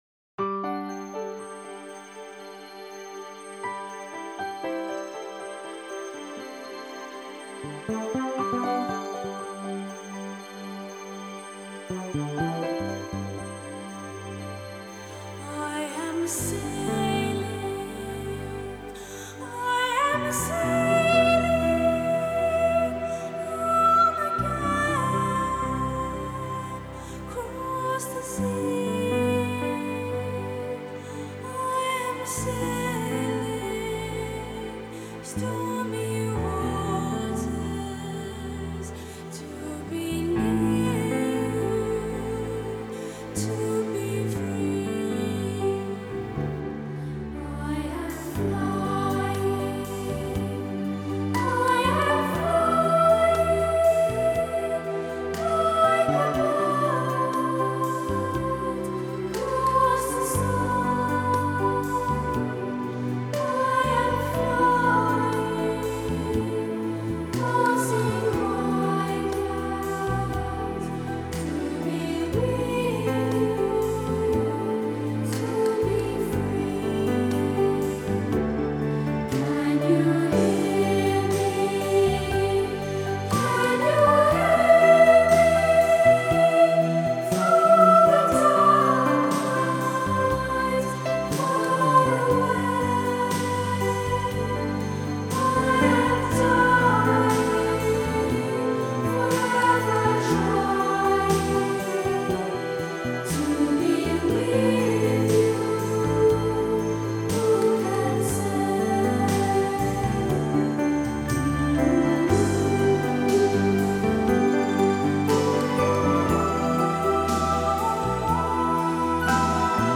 制作最高标准：96KHz/24bit取样录制
（童声领唱合唱-天堂之音，精妙绝伦，童声细致，清晰干净）